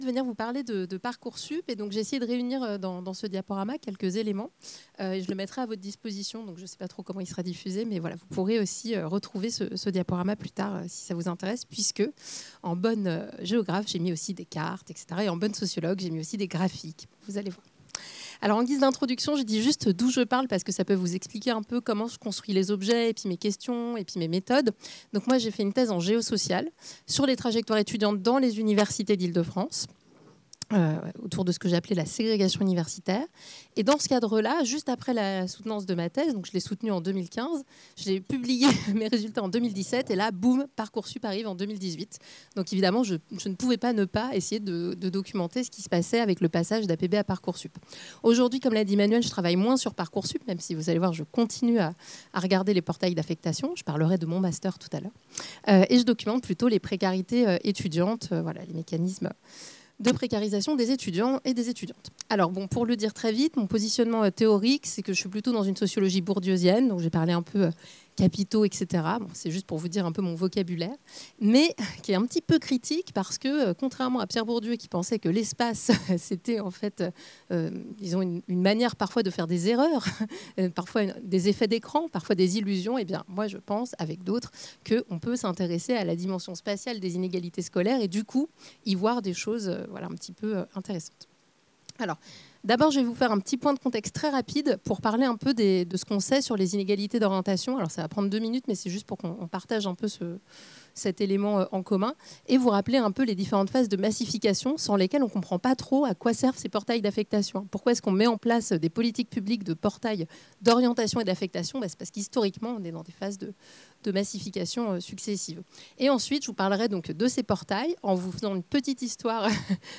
Retrouvez l'enregistrement de la conférence ainsi que le diaporama utilisé.